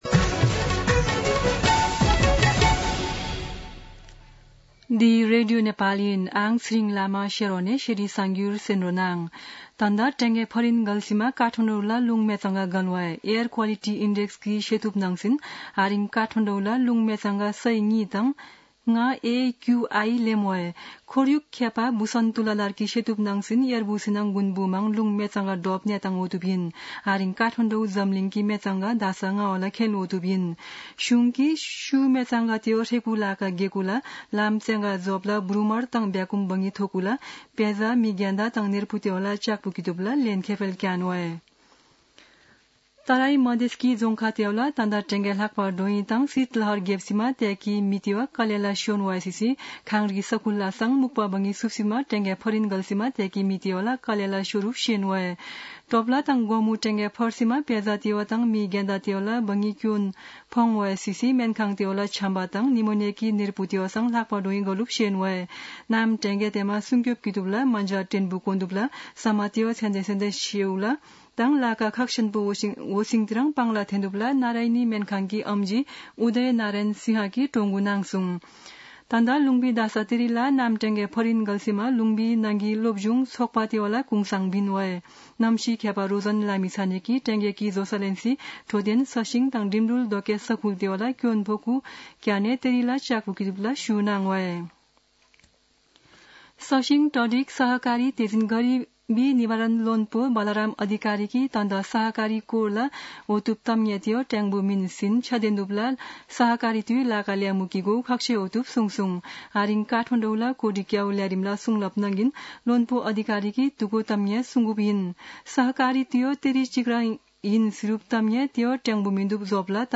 शेर्पा भाषाको समाचार : २३ पुष , २०८१
Sherpa-News-1.mp3